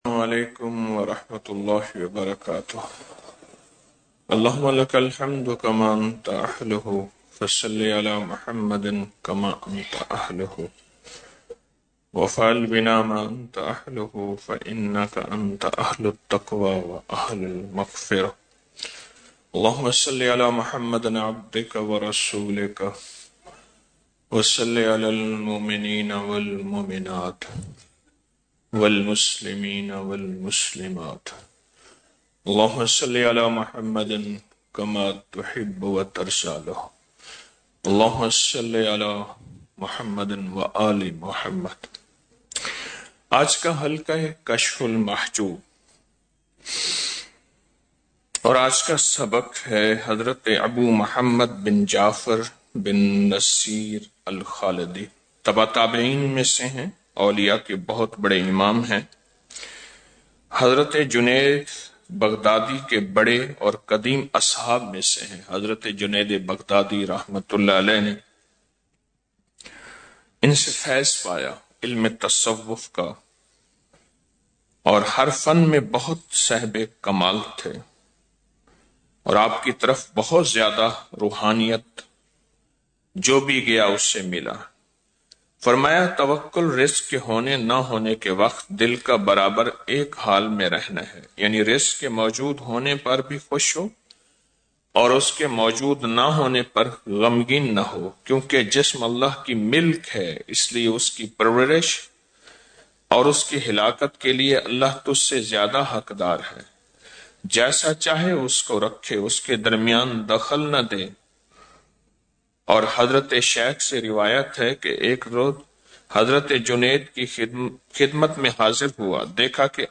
آڈیو درس